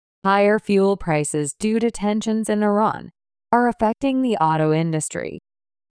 ３）スロー（前半／後半の小休止あり）